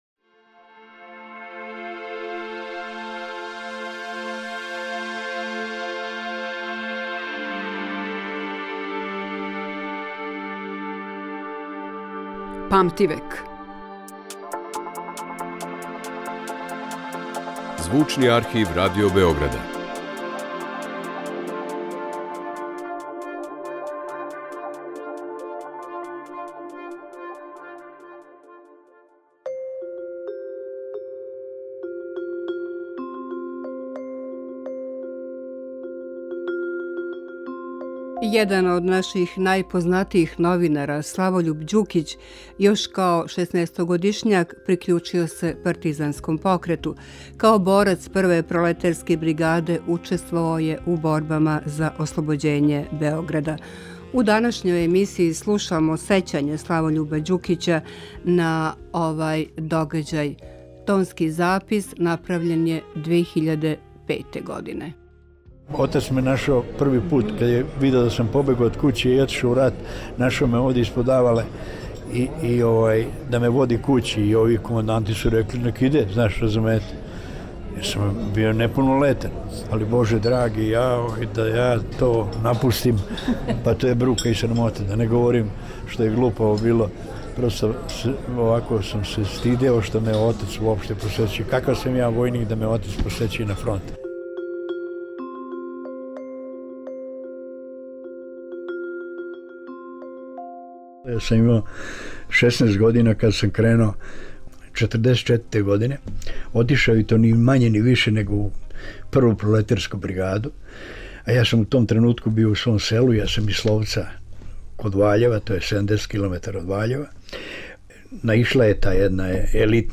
У данашњој емисији слушамо сећања Славољуба Ђукића, једног од најзначајнијих југословенских хроничара друге половине XX века, на овај догађај.
Емисија која ће покушати да афирмише богатство Звучног архива Радио Београда, у коме се чувају занимљиви, ексклузивни снимци стварани током целог једног века, колико траје историја нашег радија.